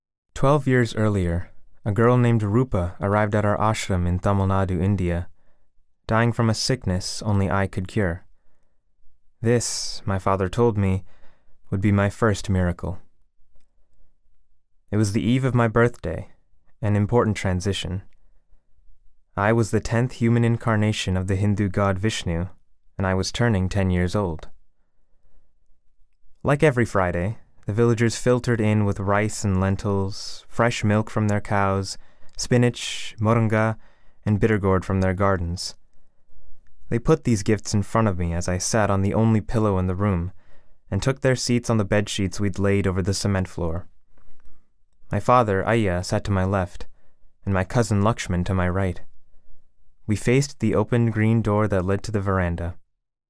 Whether it's animation, video games, commercials, or audiobooks, I've got your back in bringing your creative endeavors to life, recorded remotely from my home studio.
Audiobook - Blue-Skinned Gods